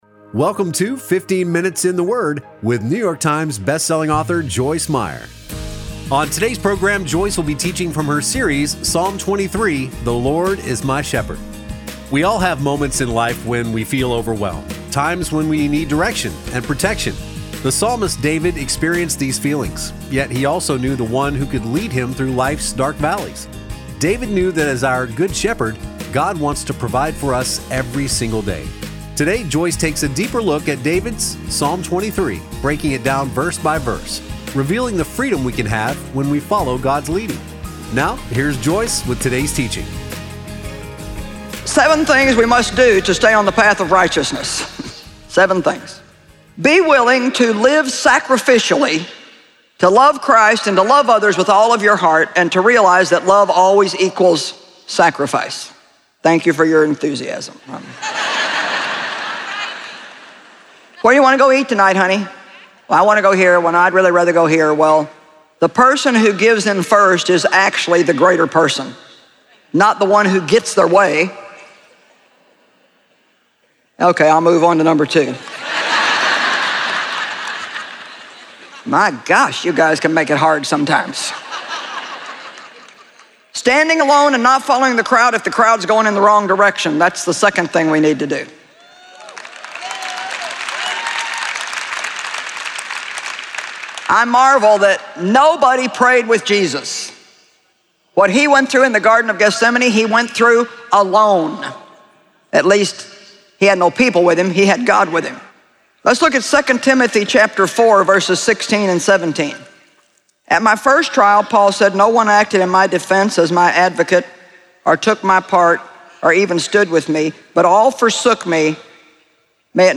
Joyce Meyer teaches on a number of topics with a particular focus on the mind, mouth, moods and attitudes. Her candid communication style allows her to share openly and practically about her experiences so others can apply what she has learned to their lives.